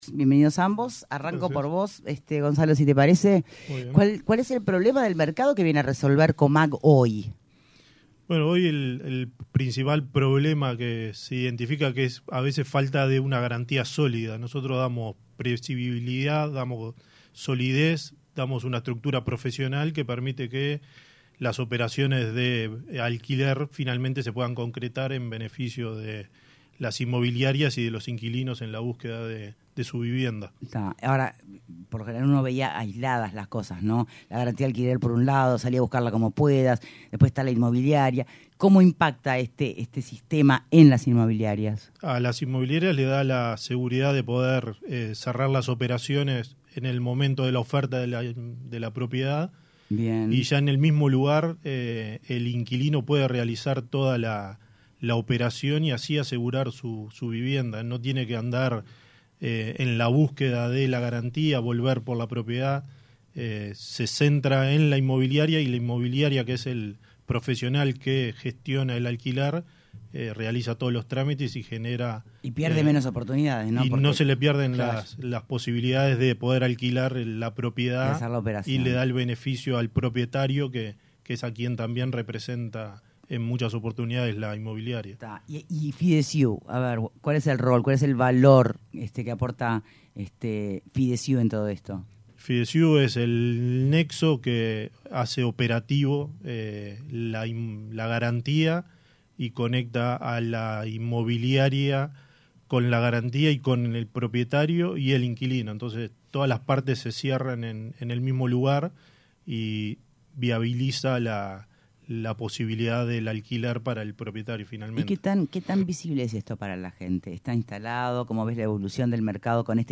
En entrevista con Punto de Encuentro, el médico y diputado por el Frente Amplio, Federico Preve habló de su proyecto de ley para regular la situación de los seguros privados ya que no compiten de la misma forma con mutualistas y ASSE.